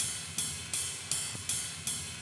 RIDE_LOOP_5.wav